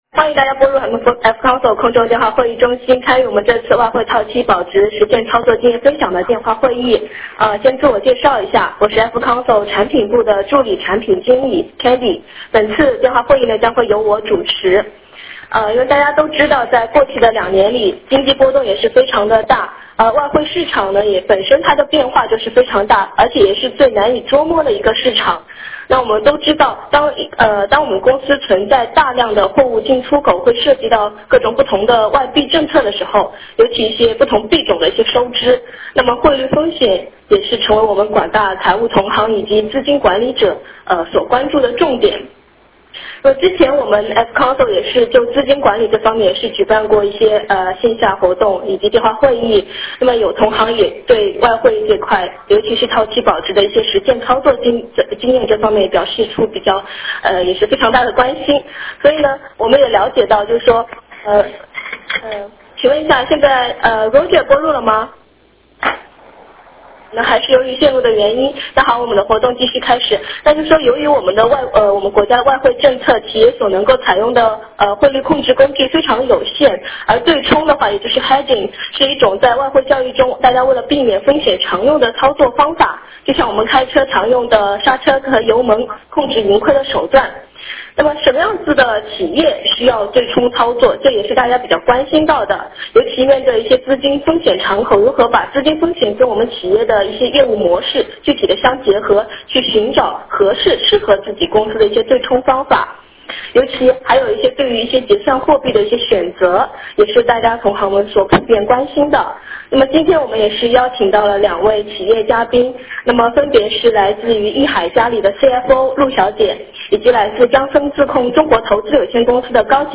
电话会议
Q&A 互动环节